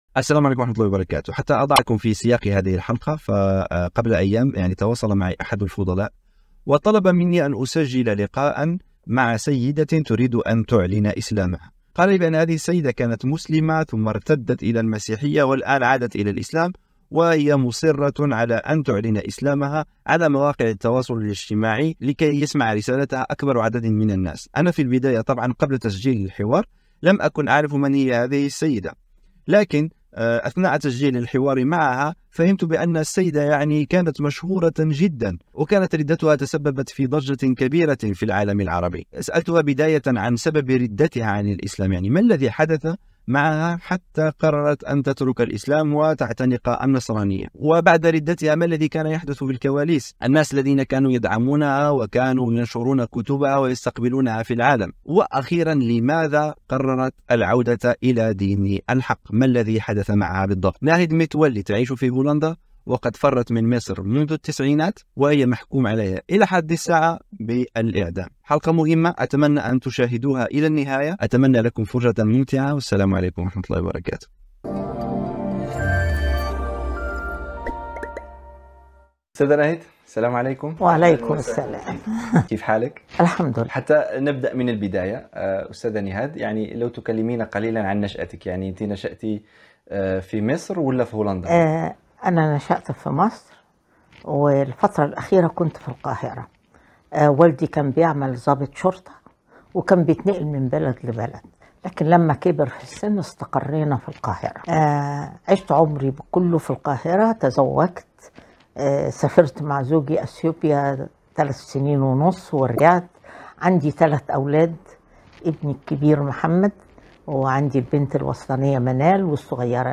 حوار حصري على السبيل
أجرى الحوار في هولندا